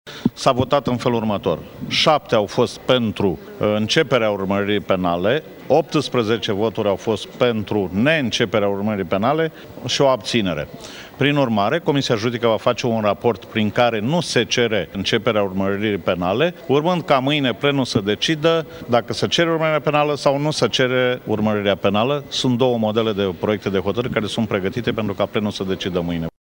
Cum au votat cei 26 de deputați din Comisia Juridică ne spune președintele comisiei, Ciprian Nica: